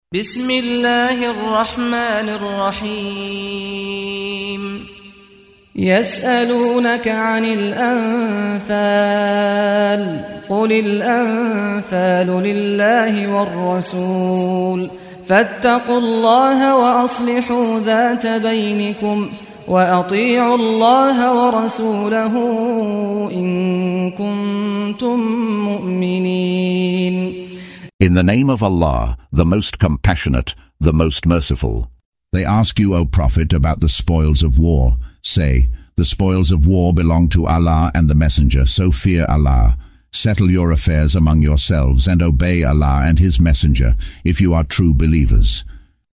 Audio version of Surah Al-Anfal ( The Spoils of War ) in English, split into verses, preceded by the recitation of the reciter: Saad Al-Ghamdi.